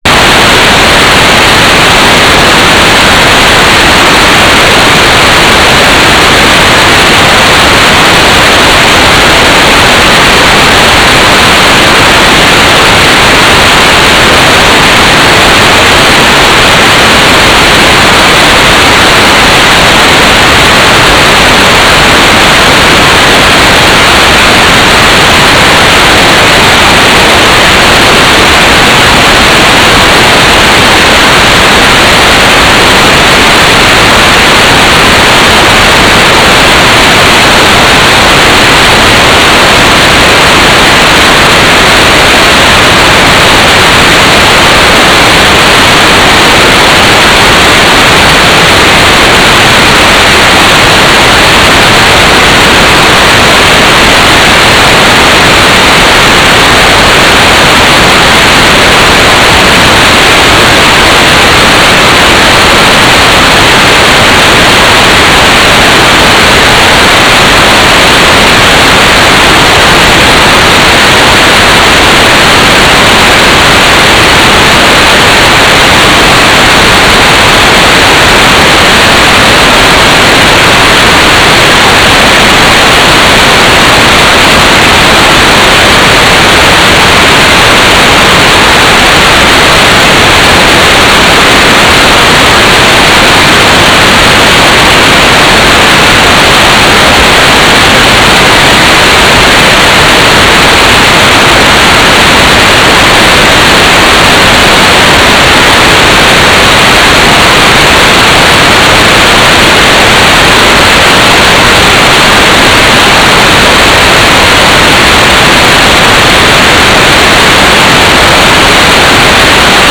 "transmitter_description": "Mode U - GMSK 4k8 AX.25 TLM",
"transmitter_mode": "GMSK",